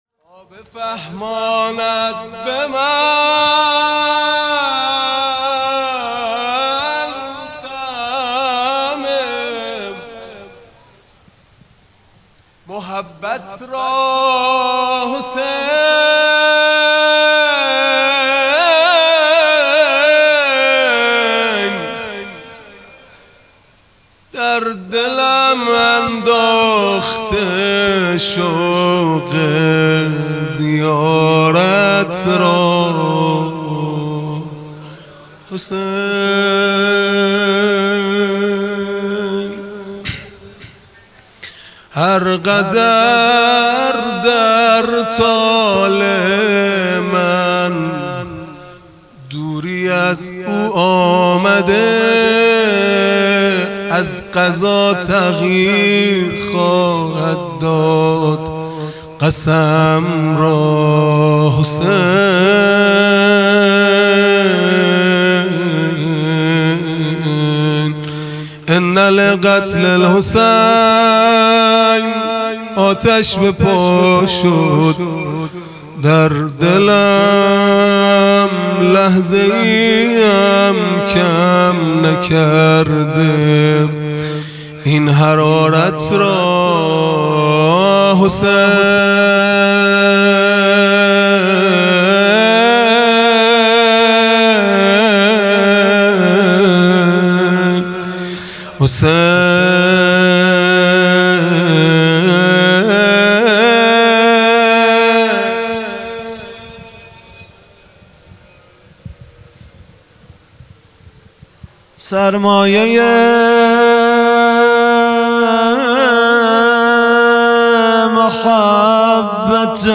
خیمه گاه - هیئت بچه های فاطمه (س) - مناجات_پایانی | تا بفهماند به من طعم محبت را حسین | 31 تیرماه 1402